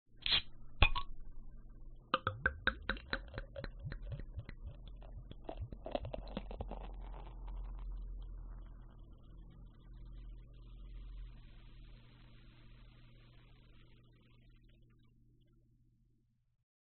免费的啤酒开瓶和浇灌
描述：嗨，两段打开一瓶黑啤酒（德国Köstrizer啤酒）并将其倒入玻璃杯的录音，录音是用两个不同的玻璃杯完成的。
Tag: 效果